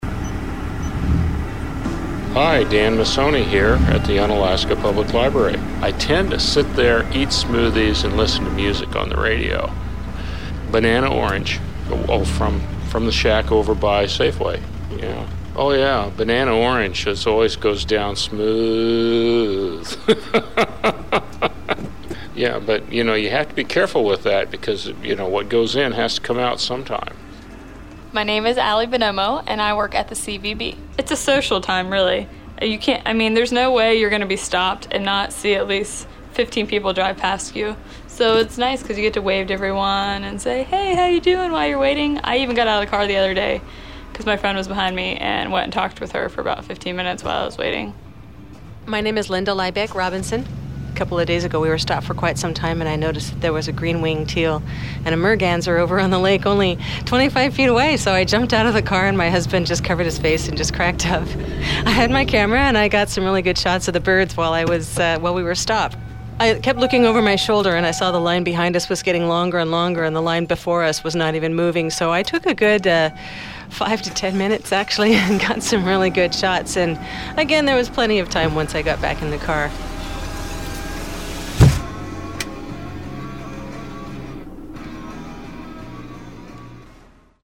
Unalaska is now halfway through a month-long repaving project that’s caused plenty of traffic delays. Here, in their own words, residents describe how they’re making the most of their time stuck in gridlock.